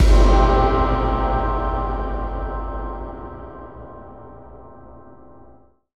Synth Impact 17.wav